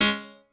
piano2_17.ogg